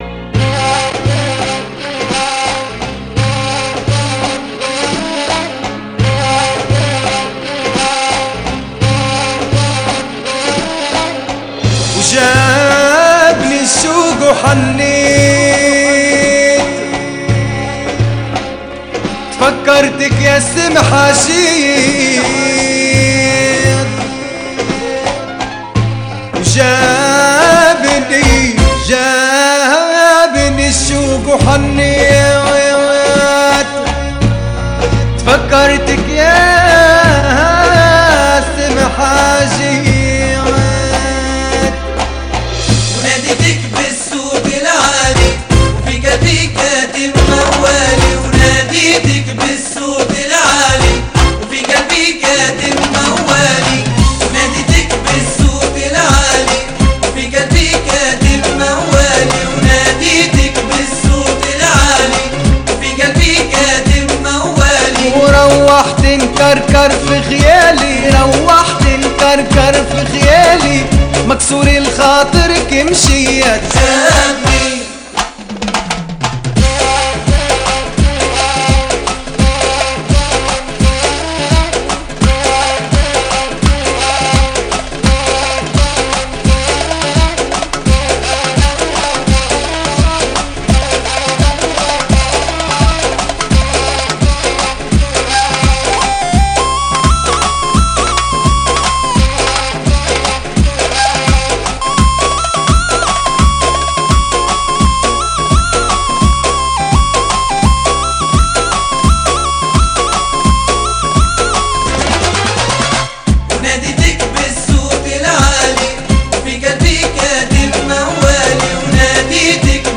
Chanteurs
Bienvenue au site des amateurs de Mezoued Tunisien